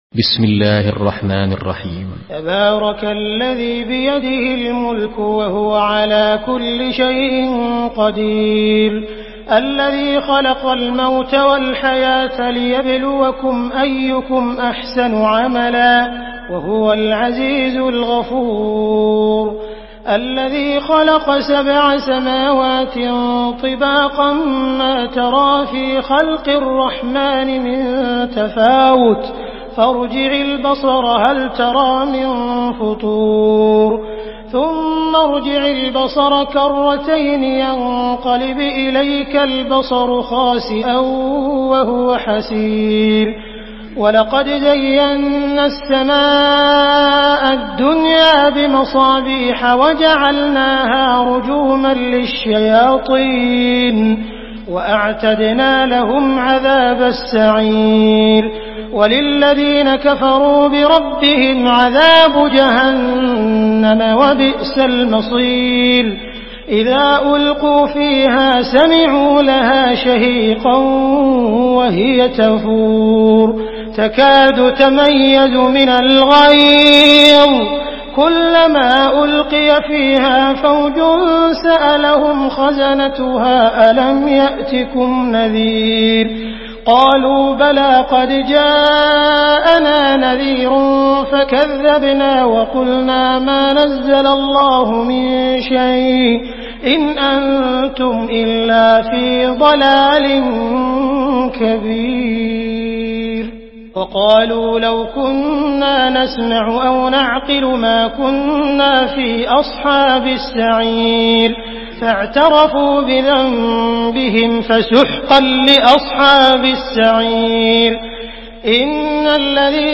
سورة الملك MP3 بصوت عبد الرحمن السديس برواية حفص
مرتل حفص عن عاصم